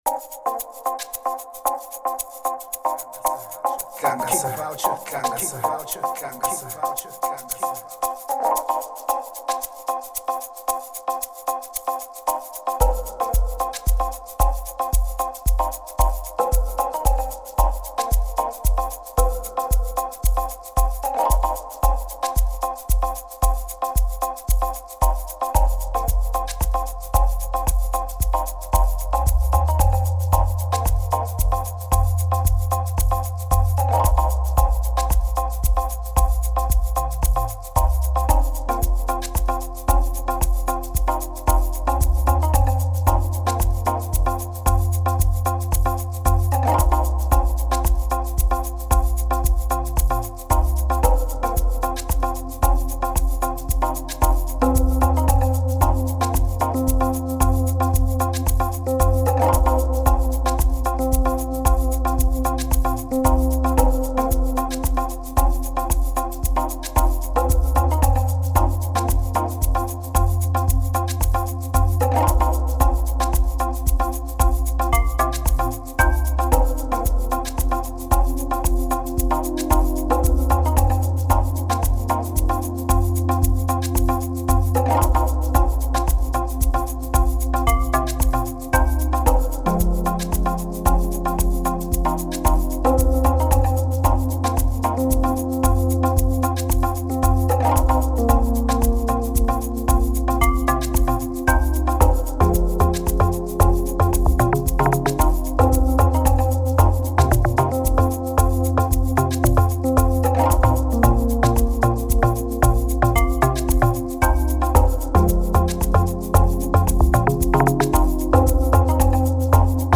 07:26 Genre : Amapiano Size